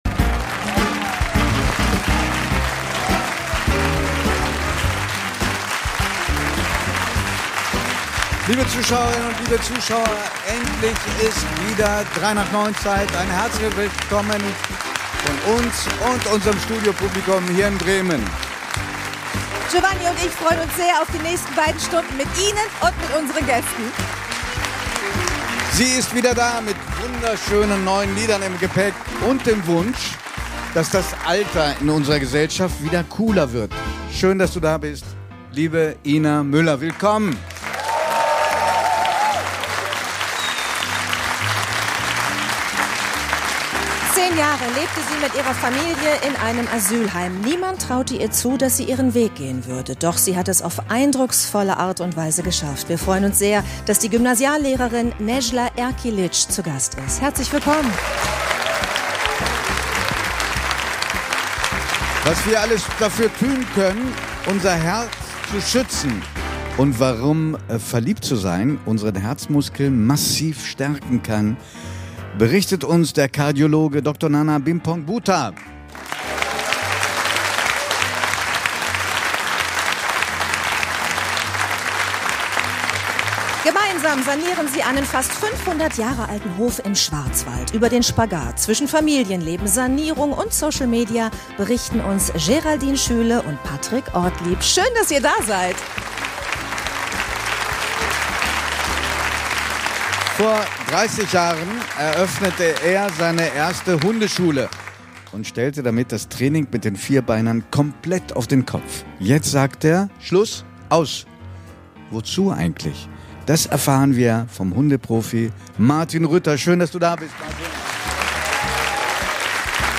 3nach9 | 14.11.2025 ~ 3nach9 – Der Talk mit Judith Rakers und Giovanni di Lorenzo Podcast